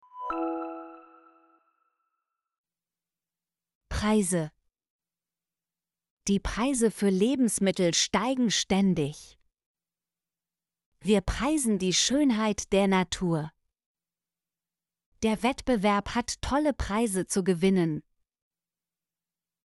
preise - Example Sentences & Pronunciation, German Frequency List